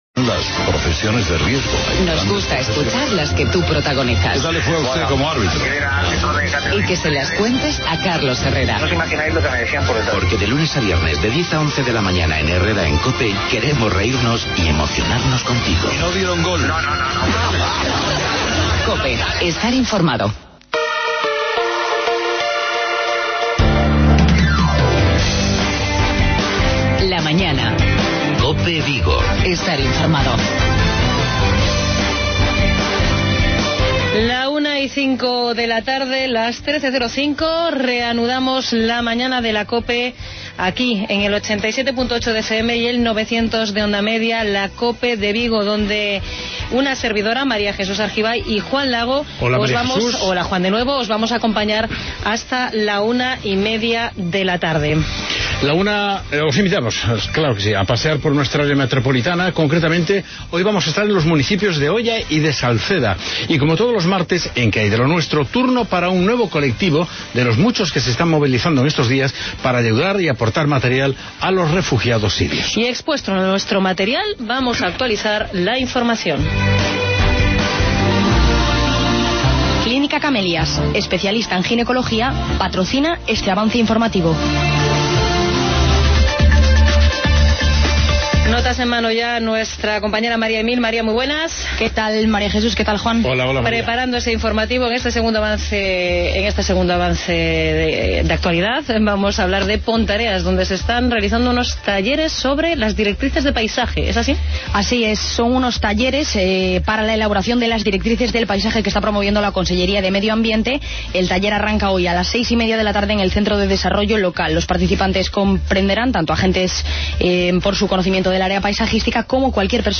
Tiempo para desplazarnos hasta los concellos del entorno, en nuestra sección de Área Metropolitana. Hoy charlamos con los regidores de Oia (Cristina Correa) y Salceda de Caselas (Marcos Besada).